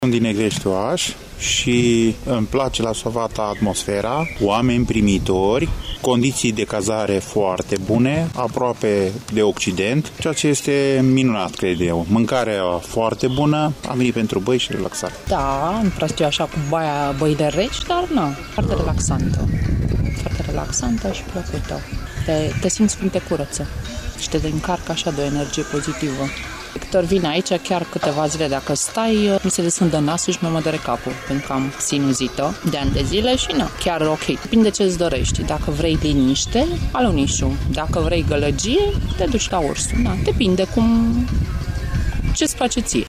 Turiştii sunt încântaţi atât de staţiunea în sine, cât şi de baia în Lacul Aluniş: